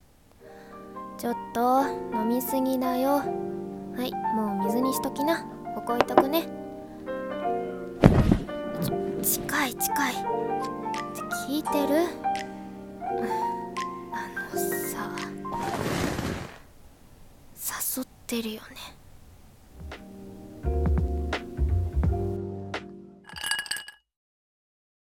誘ってるよね 【シチュボ 1人声劇】